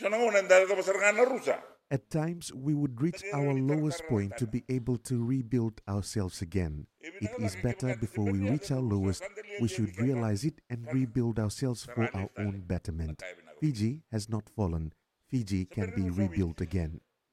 Prime Minister Sitiveni Rabuka made this comment while speaking to civil servants at Naulumatua House in Bua yesterday.